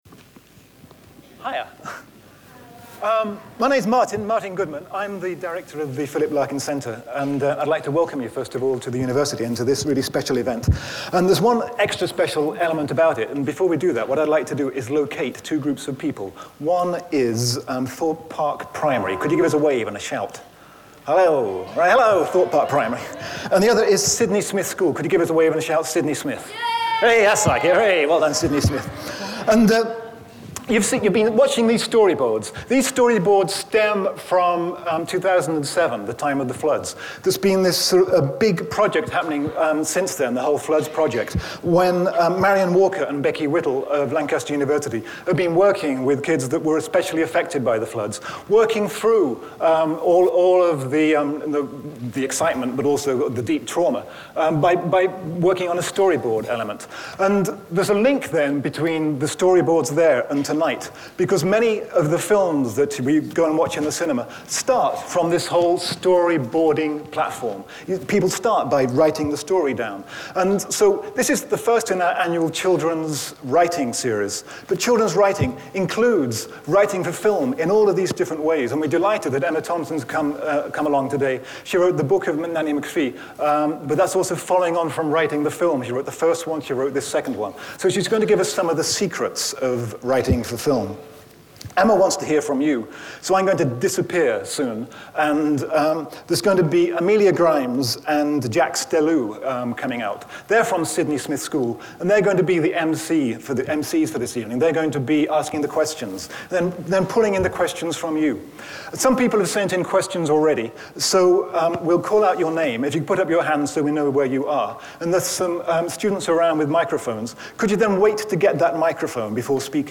The actor and writer, Emma Thompson, comes to Hull for the first annual children’s writing events. Emma stars as the returning Nanny McPhee in the film Nanny McPhee and the Big Bang, and also wrote the script.